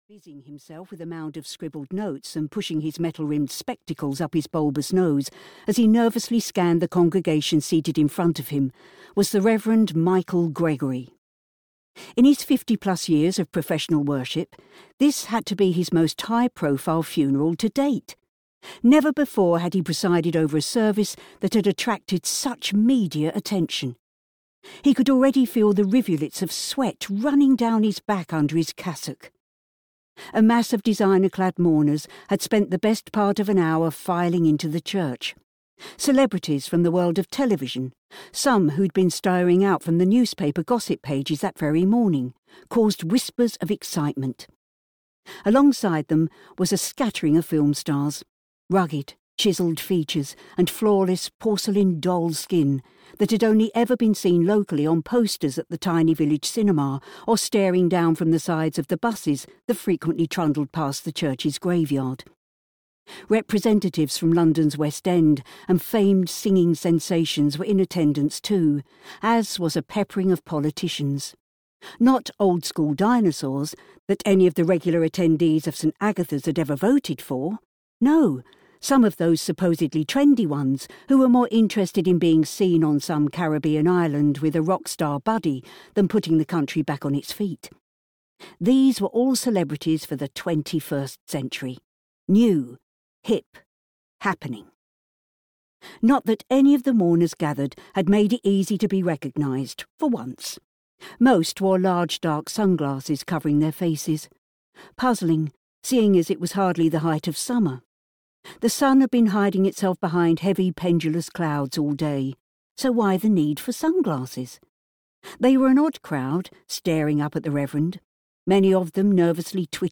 Addicted (EN) audiokniha
Ukázka z knihy